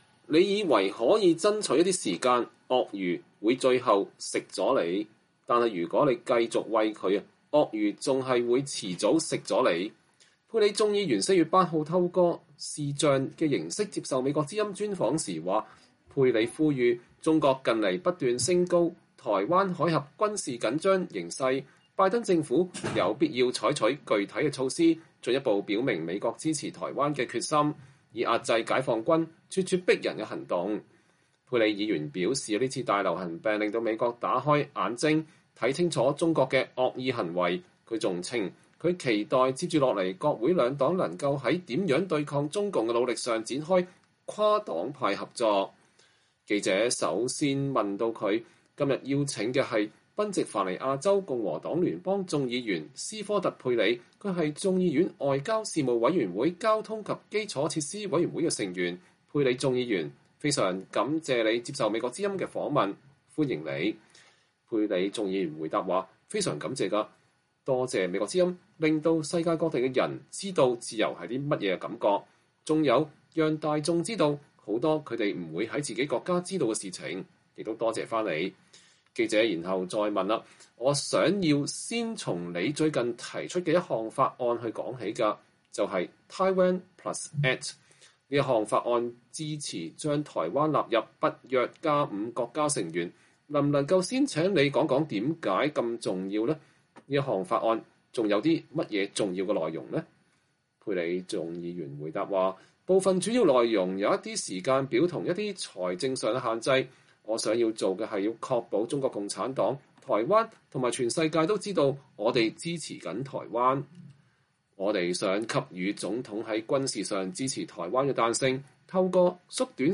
專訪共和黨眾議員佩里談中共：“你以為餵牠能爭取時間，但鱷魚遲早會吃掉你”